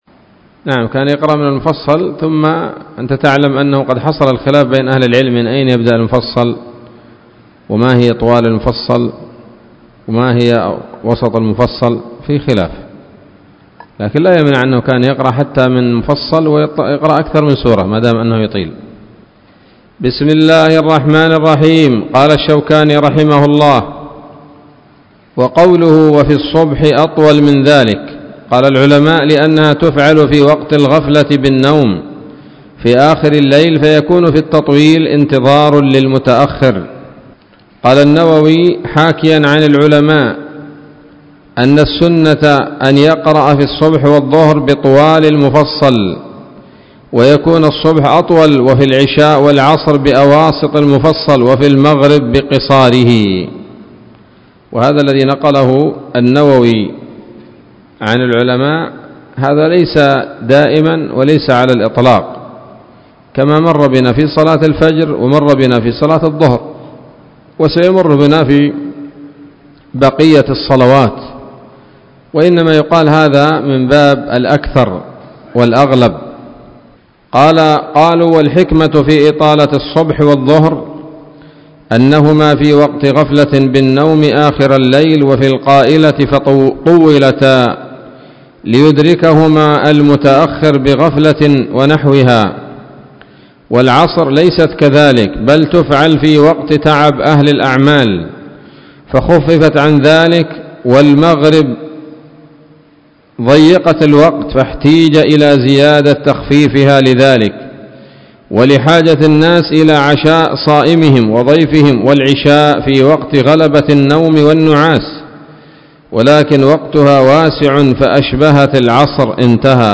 الدرس السابع والأربعون من أبواب صفة الصلاة من نيل الأوطار